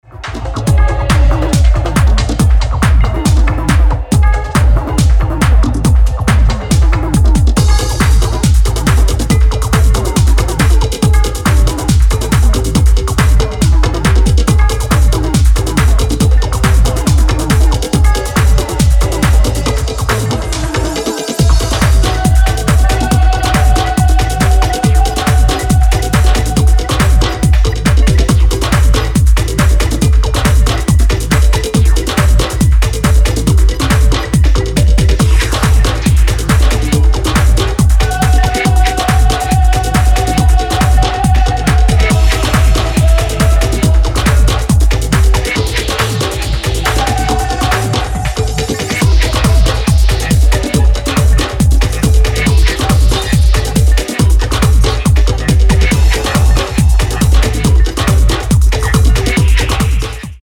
ダークで狂騒的なハウス/テクノを展開しています。